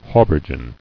[hau·ber·geon]
Hau*ber"ge*on , n. See Habergeon.